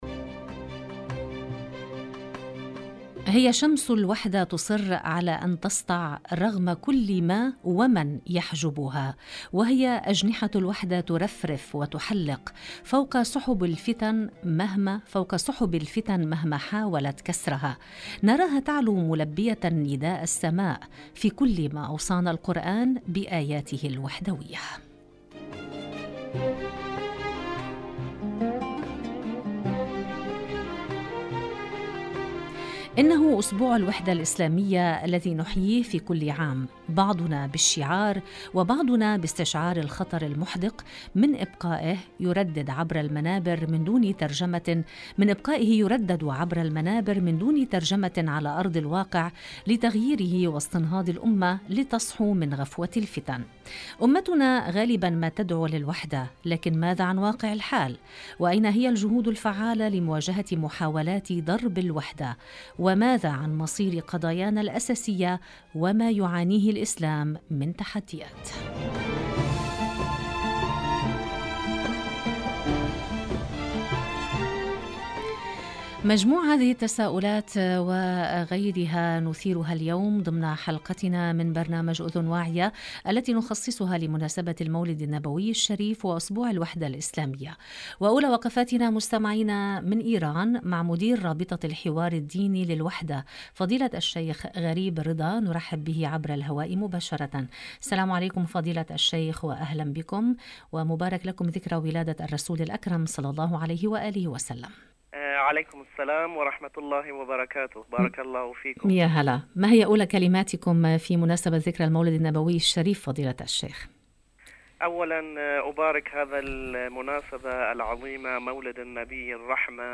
المقابلات